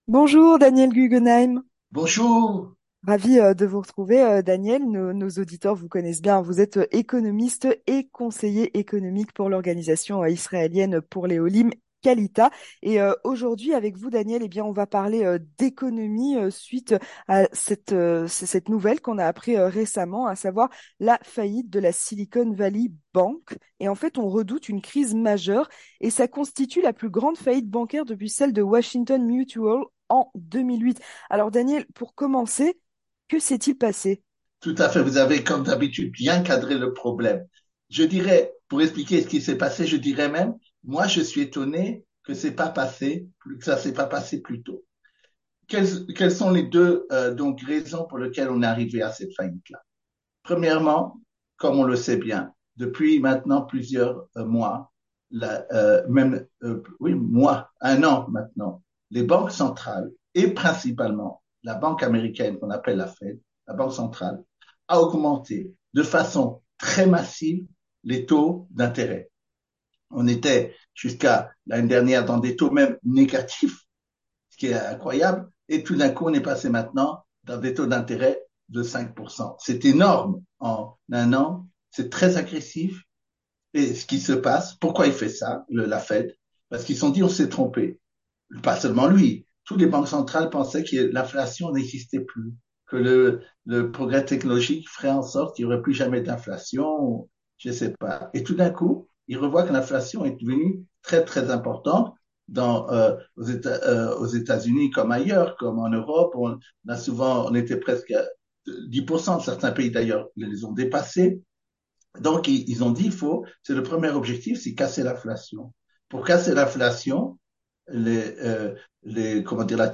Entretien du 18h - L'effondrement de la Silicon Valley Bank